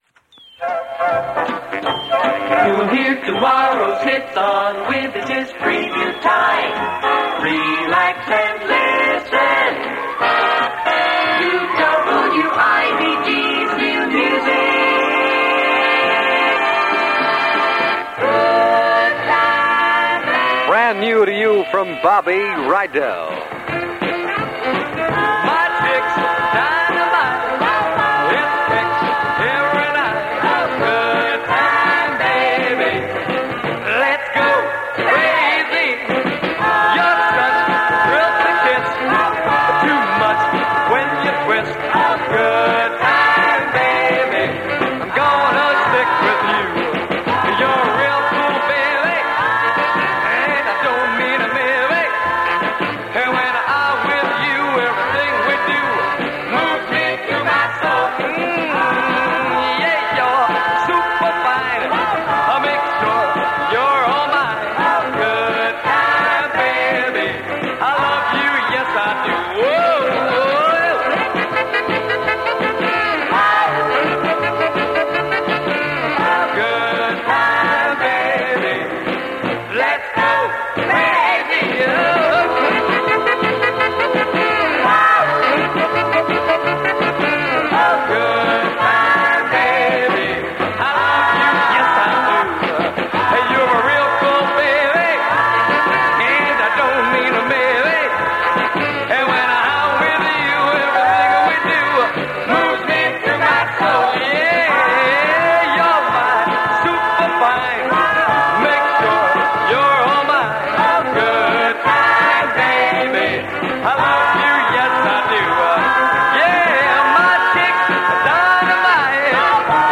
Some of it’s way over-produced – some of it is idiotic and some of it fairly aches with teenage sentiment. But this was what mainstream Pop Music Radio was all about in 1961.